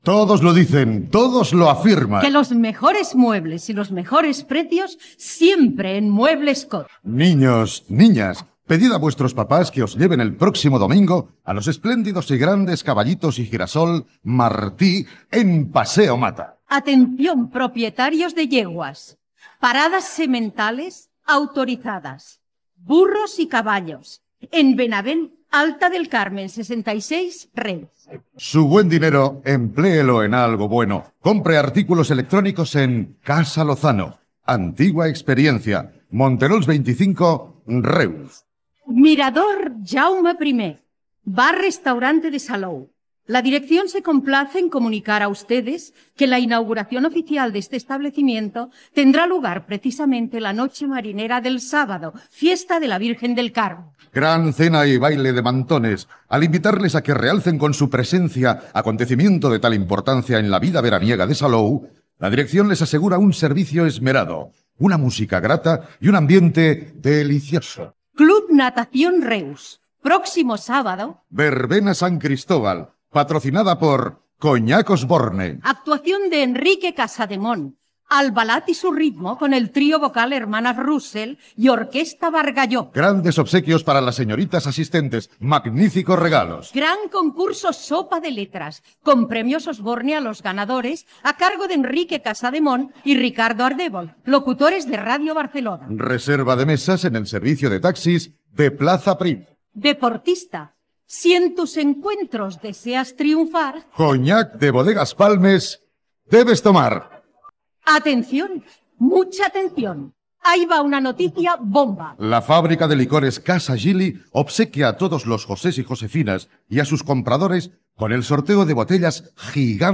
Recreació de la lectura d'anuncis publicitaris, feta en directe, tal com es feia a la dècada de 1950
La recreació es va fer dins del programa especial "Amb tu" fet el 24 d'abril de 2014 al Teatre Bartrina, amb el que Ràdio Reus commemorava el 90è aniversari de l'arribada de la ràdio a les comarques meridionals catalanes.